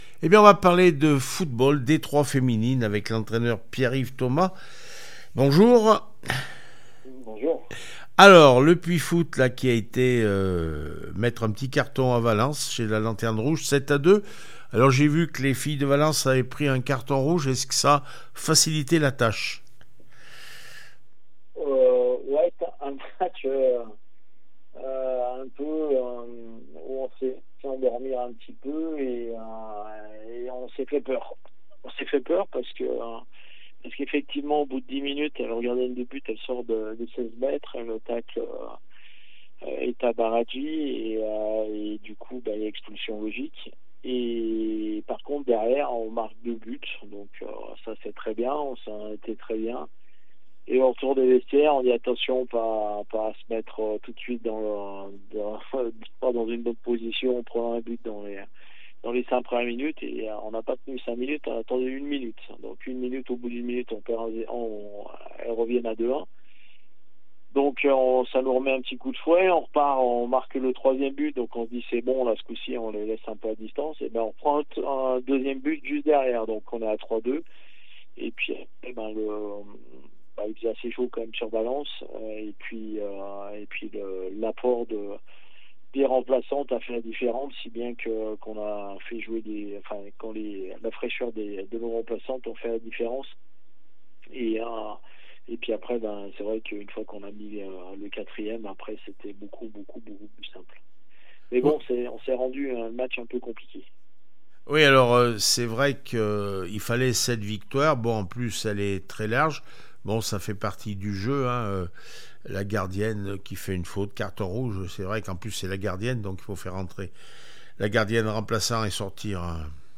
d2 féminine foot valence 2-7 le puy foot 43 réaction après match